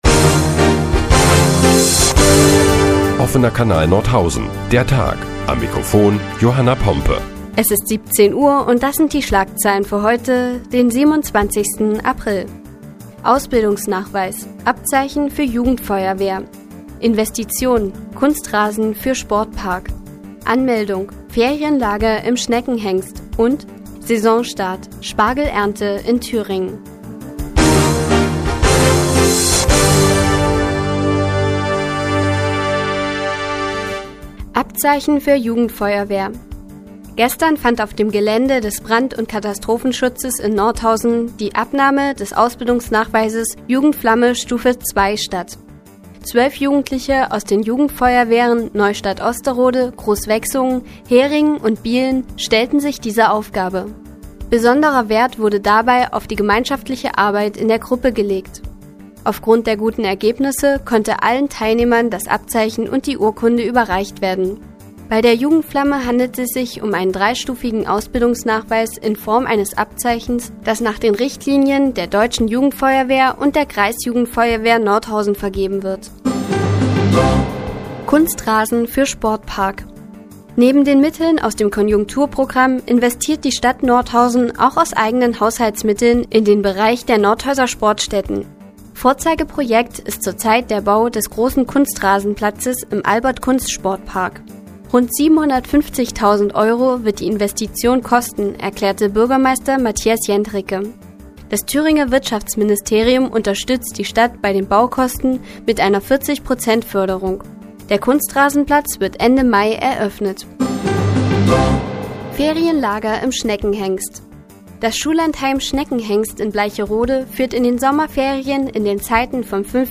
Die tägliche Nachrichtensendung des OKN ist nun auch in der nnz zu hören. Heute geht es unter anderem um Abzeichen für die Jugendfeuerwehr und Ferienlager im Schneckenhengst.